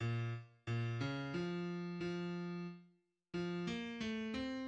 {\clef bass \tempo 4=90 \key bes \minor \set Score.currentBarNumber = #1 \bar "" bes,8 r bes, des f4 f r f8 bes a c' }\addlyrics {\set fontSize = #-2 } \midi{}